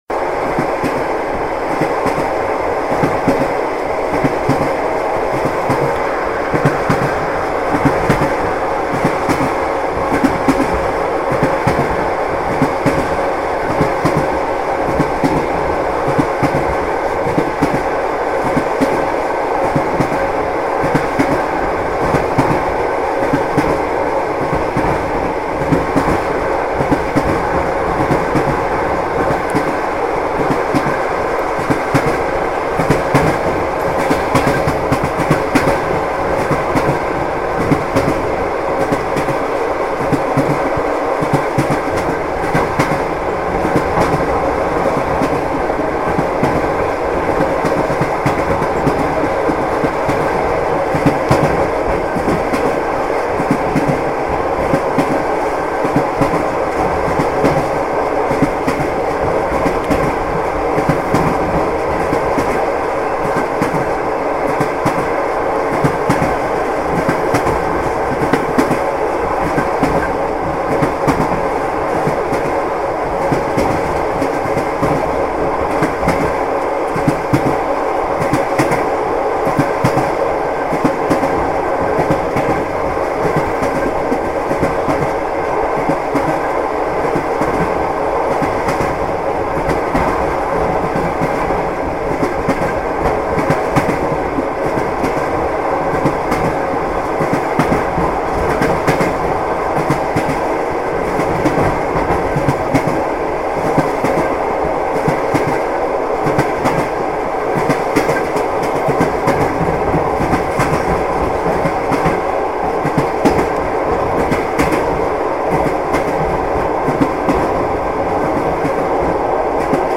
Звуки поезда
Звук эшелона, громкий уход